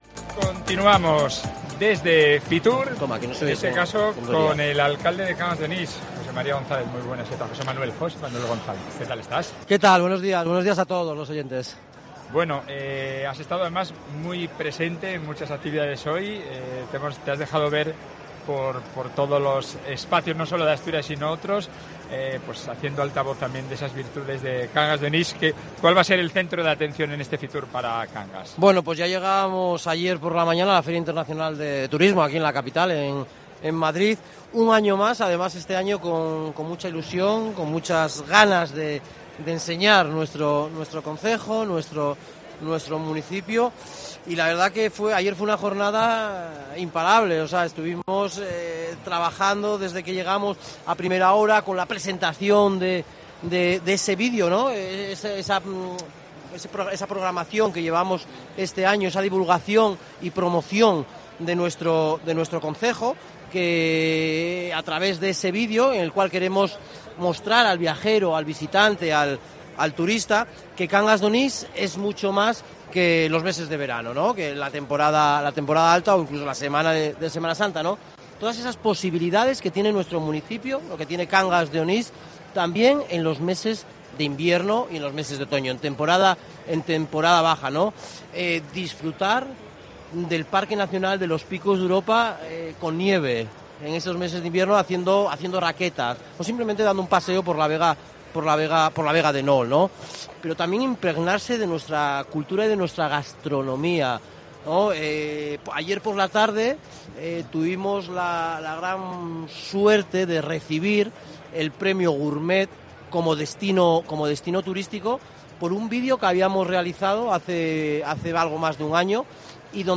FITUR 2024: Entrevista a José Manuel González, alcalde de Cangas de Onís